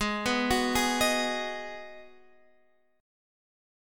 Abm7 chord